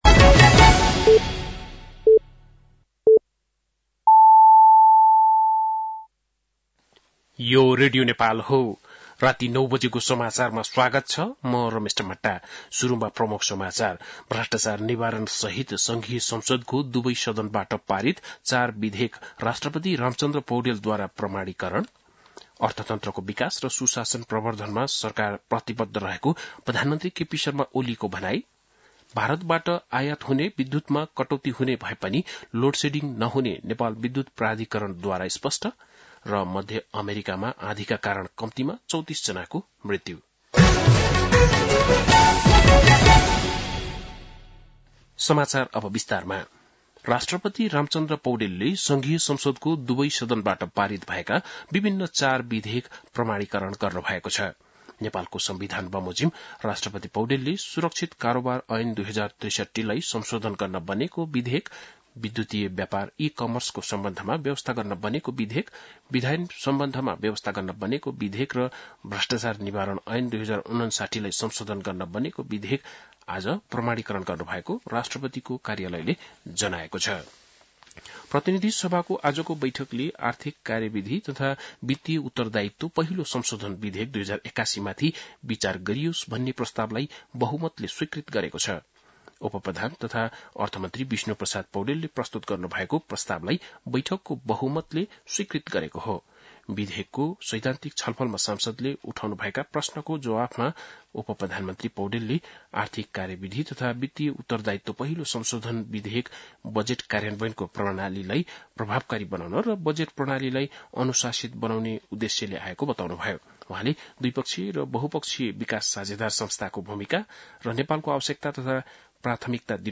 बेलुकी ९ बजेको नेपाली समाचार : ३ चैत , २०८१
9-pm-nepali-news-12-03.mp3